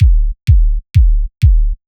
Sub kick Free sound effects and audio clips
• Tight Sub Kick with Reverb.wav
Tight_Sub_Kick_with_Reverb_0mt.wav